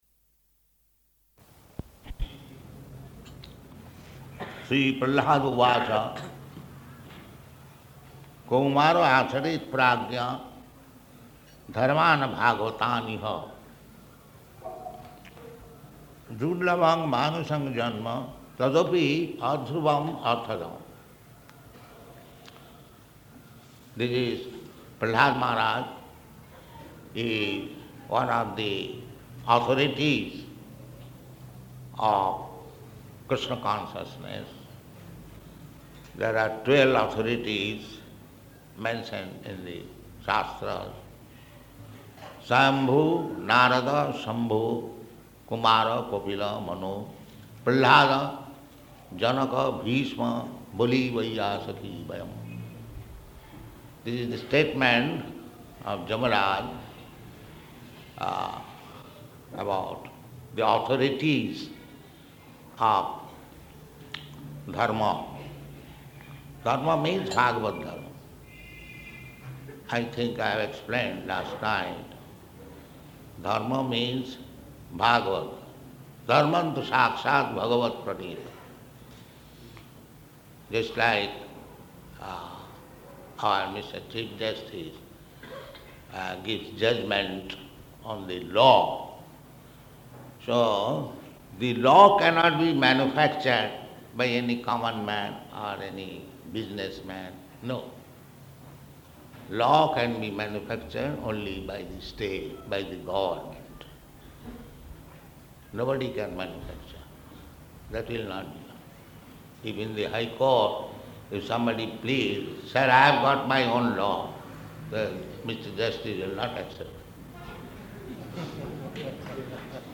Location: Madras